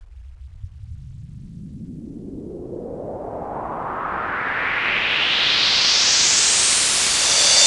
cch_fx_loop_dmx_125.wav